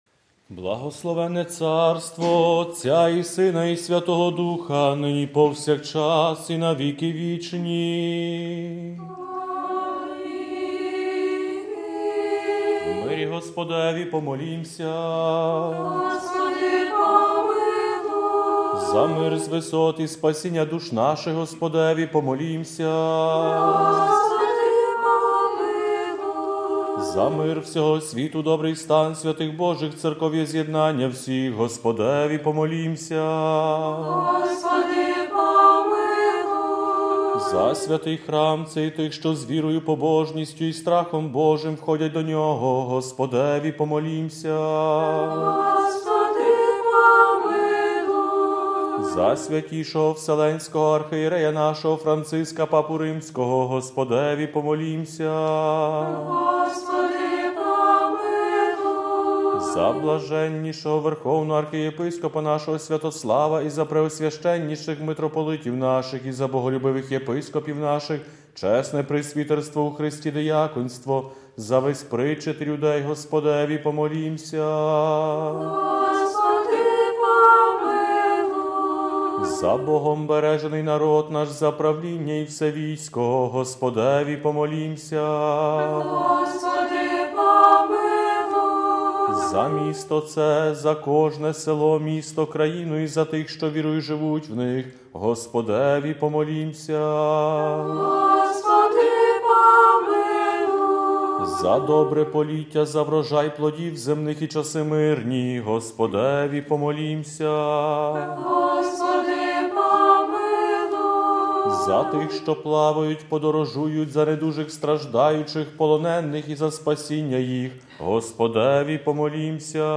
Свята Літургія на Ватиканському Радіо 30 жовтня 2016 р.
Співали Сестри Чину Святого Василія Великого.